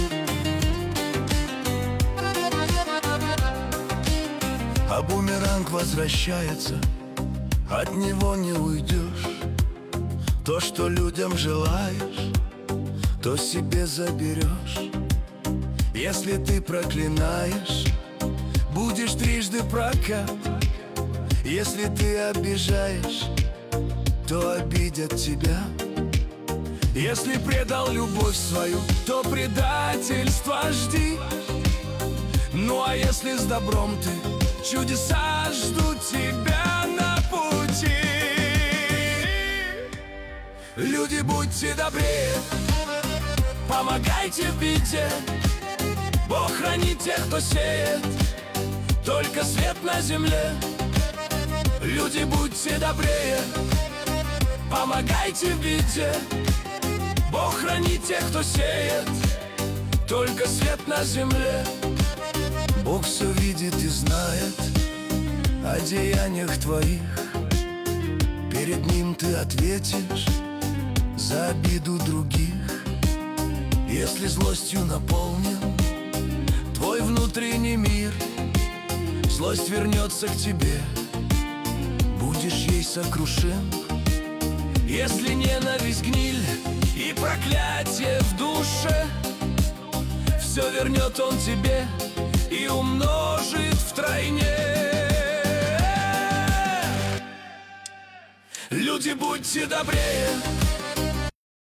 Качество: 320 kbps, stereo
Нейросеть Песни 2025